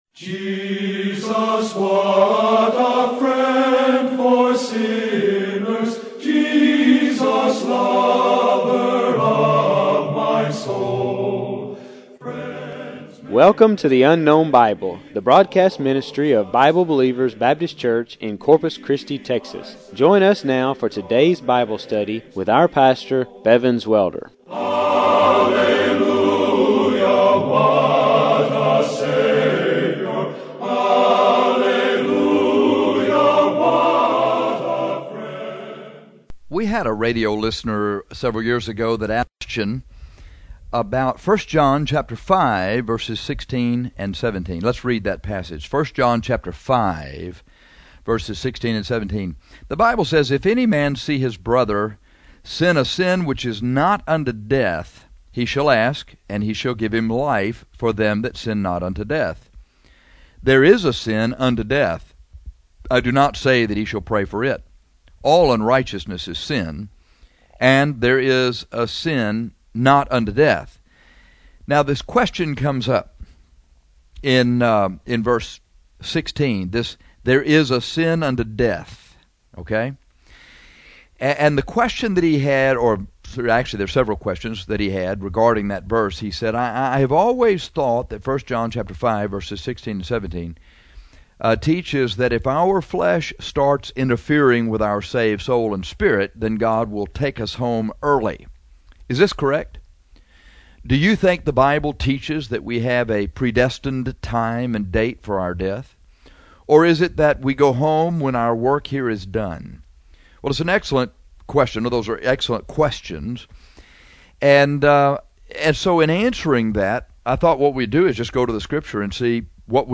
This broadcast is titled Life Cut Short because it deals with the question of whether your life will be cut short by sin.